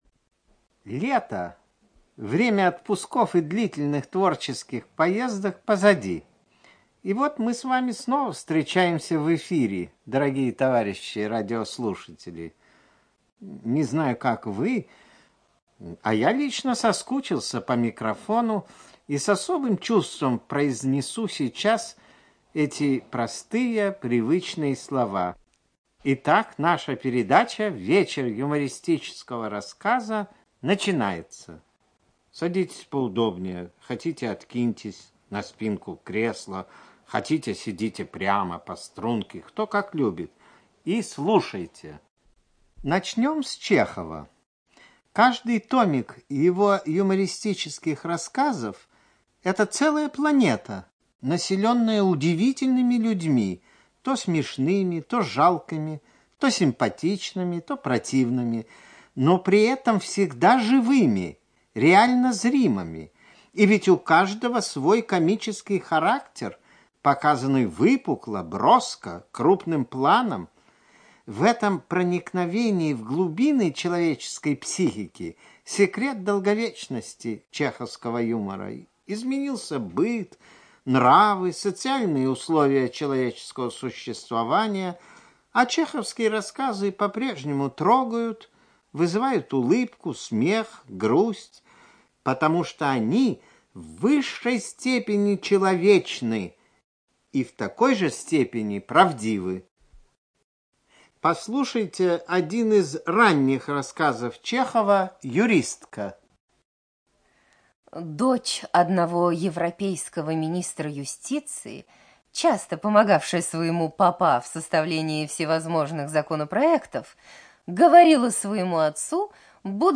ЧитаютИванов Б., Папанов А., Миронов А., Лепко В., Ленч Л.